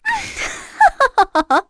Erze-Vox_Happy2_kr.wav